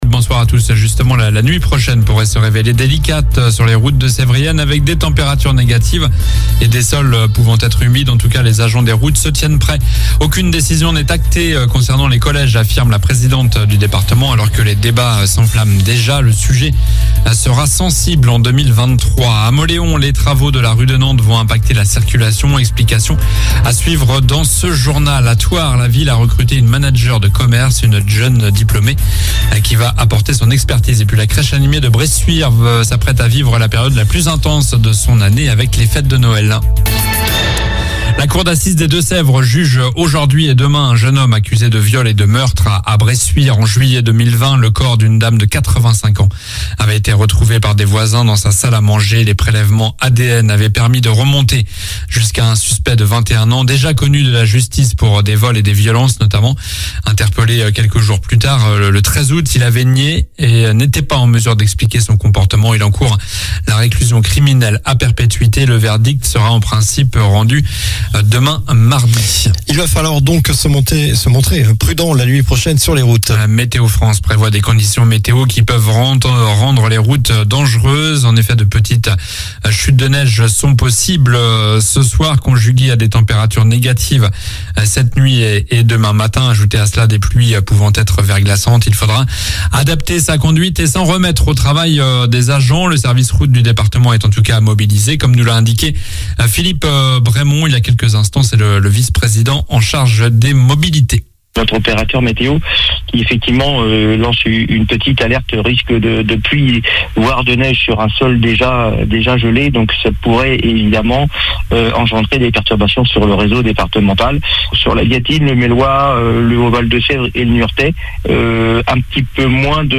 Journal du lundi 12 décembre (soir)